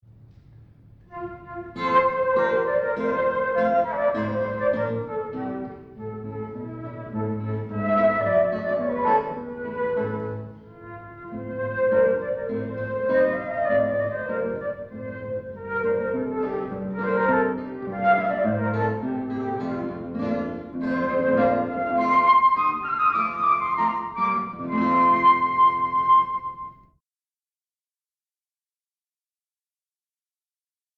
Duo flauto e chitarra
Circolo Eridano, Torino 2 Aprile 1993